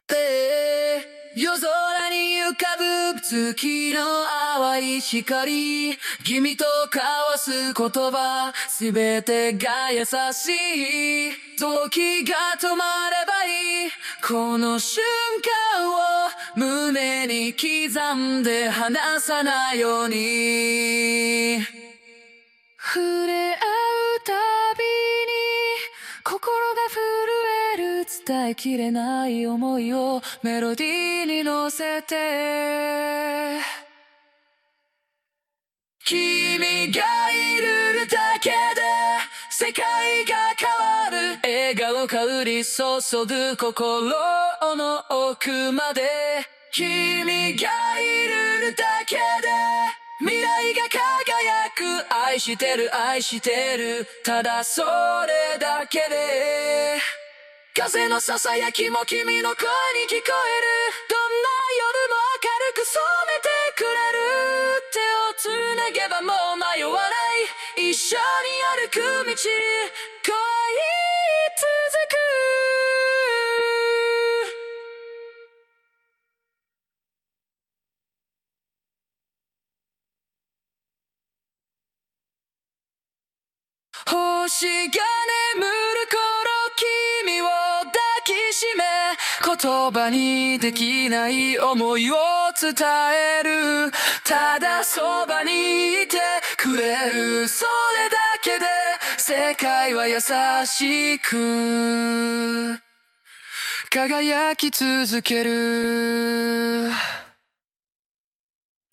▶ TopMediai 音楽ツールで「冬のラブソング」のボーカルを分離：
ai-song-Vocals.mp3